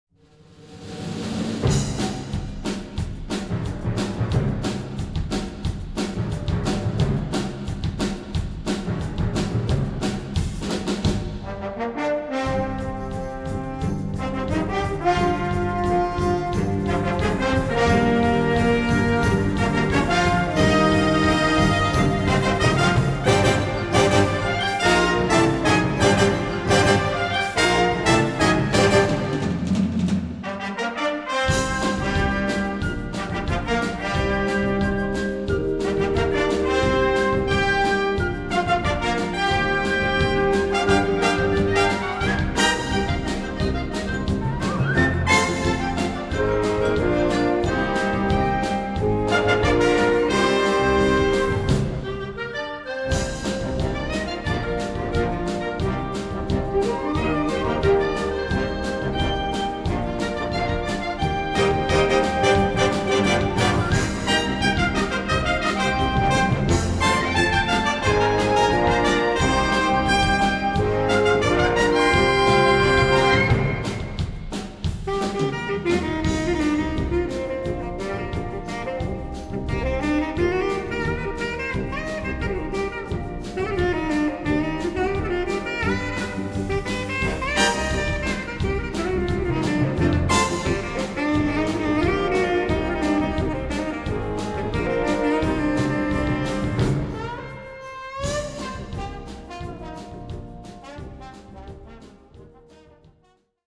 Noten für Blasorchester.
• View File Blasorchester